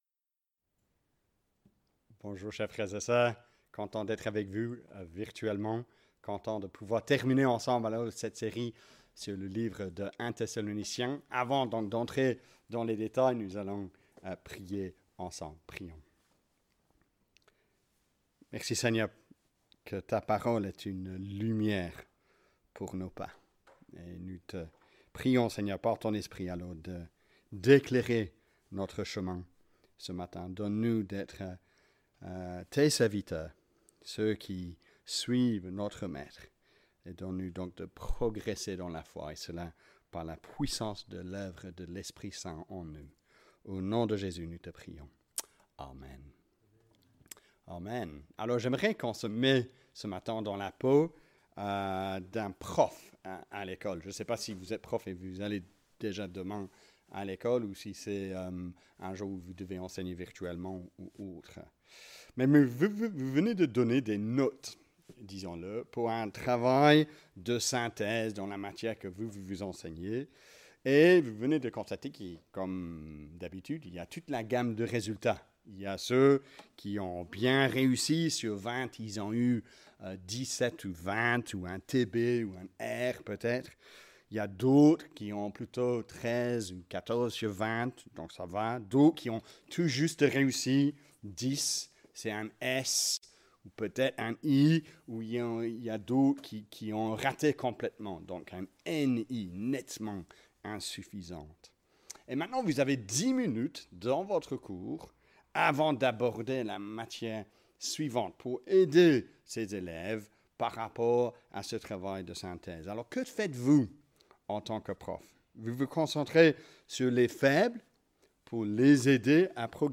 Culte-du-15-novembre-de-lEPE-Bruxelles-Woluwe.mp3